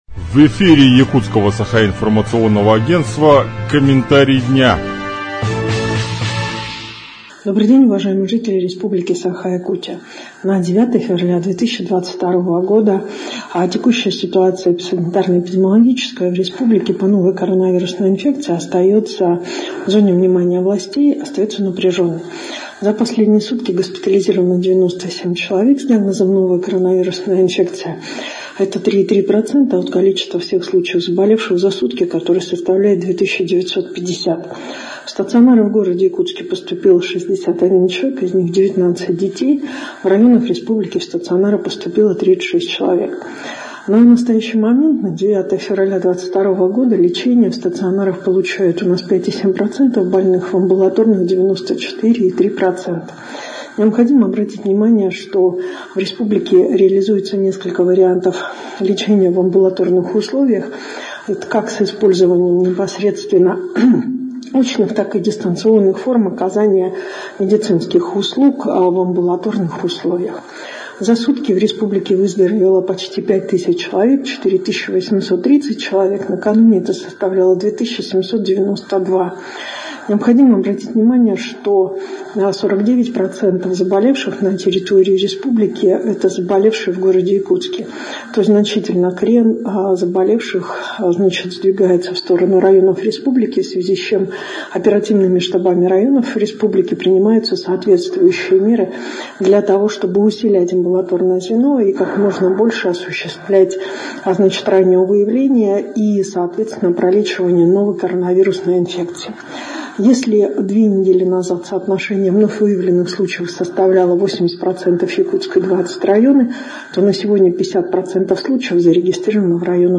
Подробнее в аудиокомментарии заместителя руководителя республиканского оперштаба, вице-премьера Якутии Ольги Балабкиной: